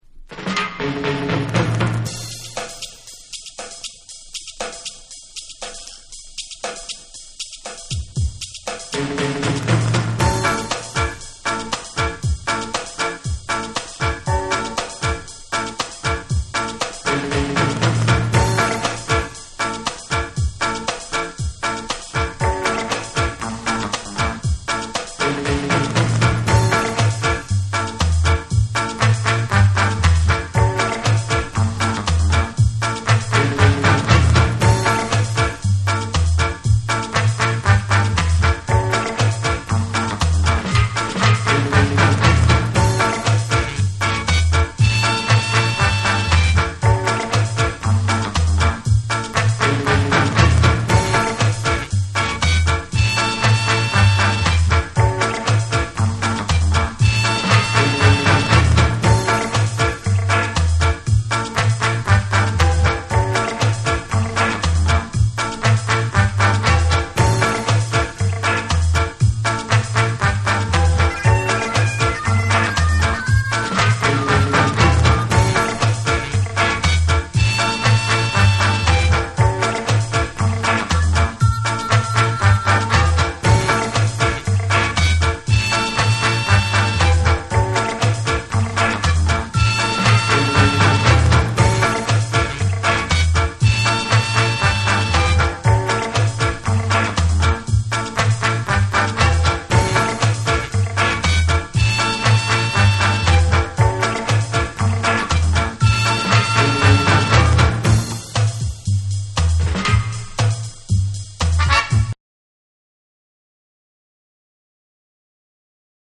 タイトル通りBIG BEATのヒット・ナンバーをコンパイルした98年リリース作。